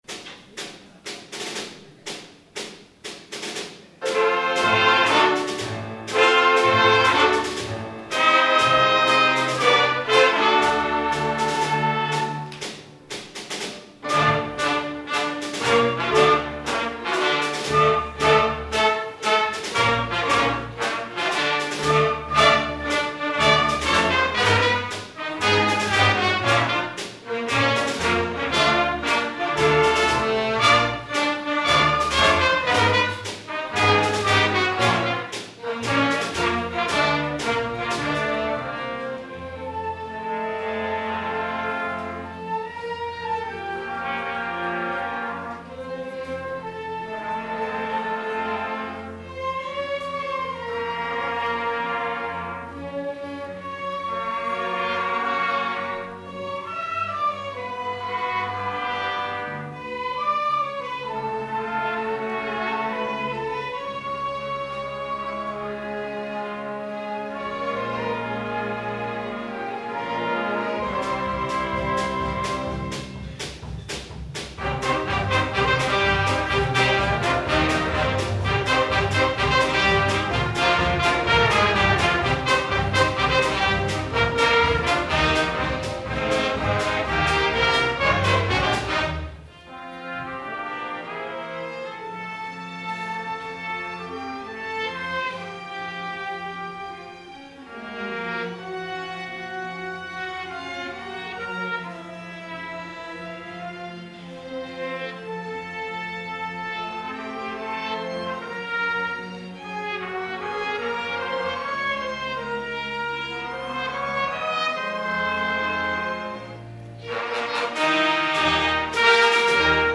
Schulkonzert am 24. und 25.5.2005: "Große Pötte" am SGH
Am 24. und 25.5.2005 organisierte das SGH wieder ein großes Schulkonzert, der Titel lautete „Große Pötte“.